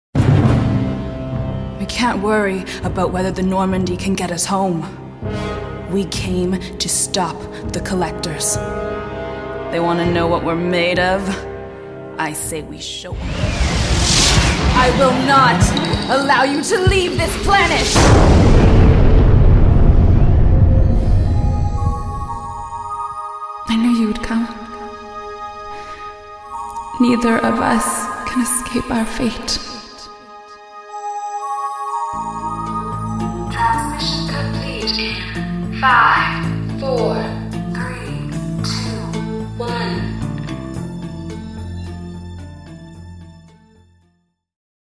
Female Voice Over talent- Years of singing and Acting Experience. Young, Fresh, Mature, Sexy, Wise.
Sprechprobe: Sonstiges (Muttersprache):